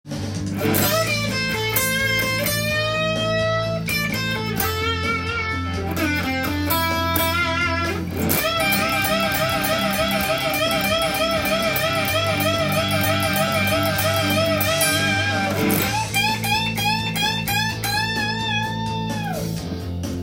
使いやすいようにAmペンタトニックスケールで譜面にしてみました。
ブルース系のフレーズになるのでペンタトニックスケールで
そして、エレキギター特有のチョーキングを多用しているのも